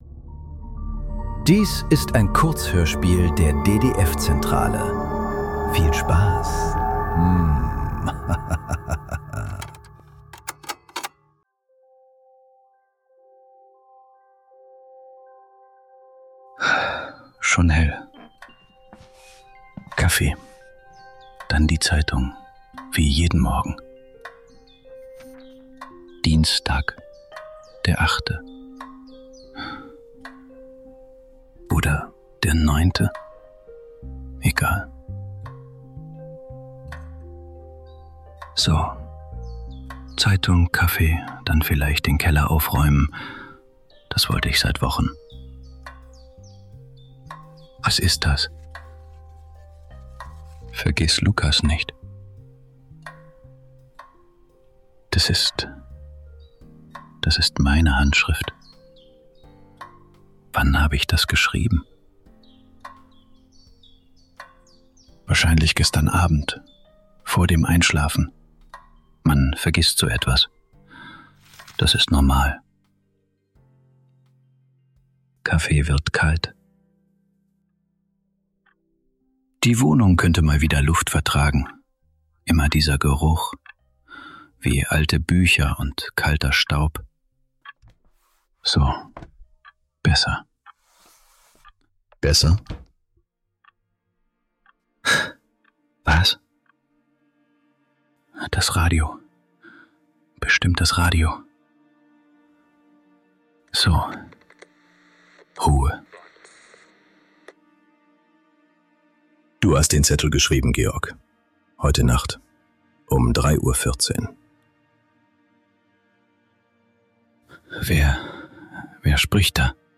Stimmen ~ Nachklang. Kurzhörspiele. Leise.